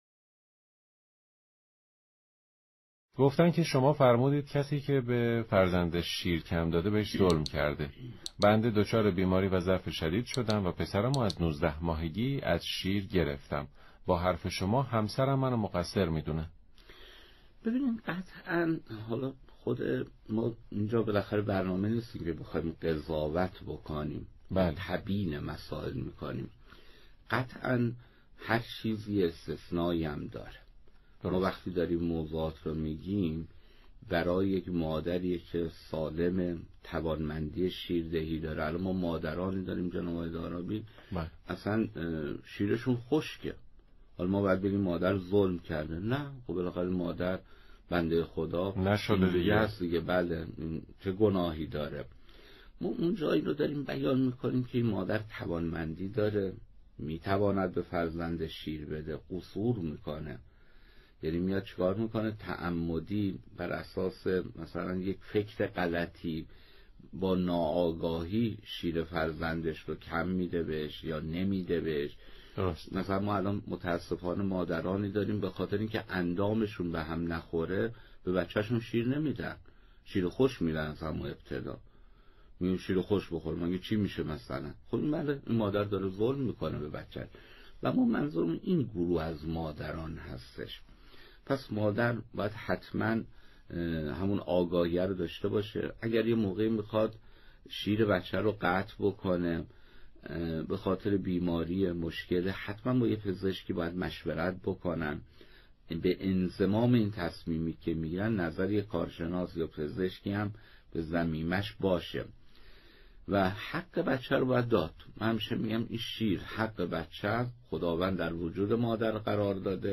در یکی از سخنرانی ها به پرسش و پاسخی در موضوع «شیرندادن مادر به خاطر بیماری یا ضعف جسمی» پرداخت که تقدیم شما فرهیختگان می شود.